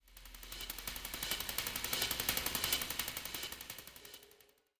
Music Transition; Conga Ethnic Drums Fade In And Out.